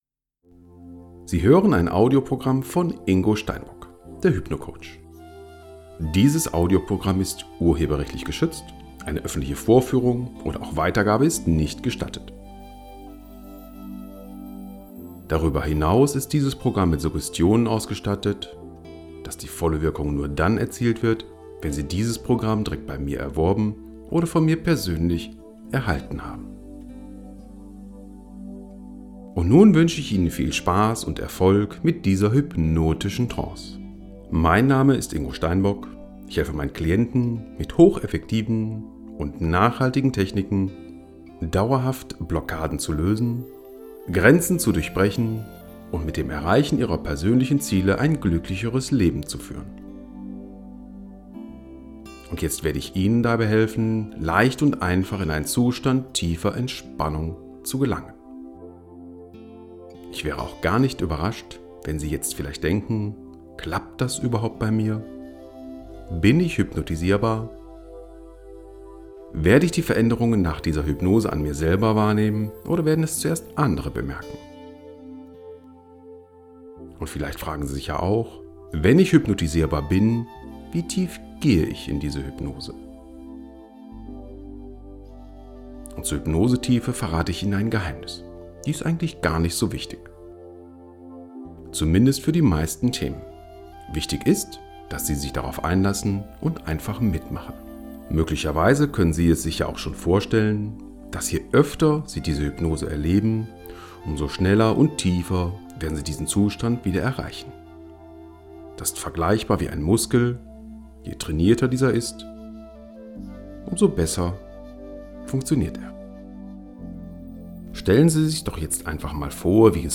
Auflösen von Prüfungsangst Bilaterales Coaching nur mit Kopfhörer anhören
Auflösen-von-Prüfungsangst-Bilaterales-Coaching-nur-mit-Kopfhörer-anhören.mp3